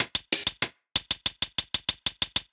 Index of /musicradar/ultimate-hihat-samples/95bpm
UHH_ElectroHatB_95-01.wav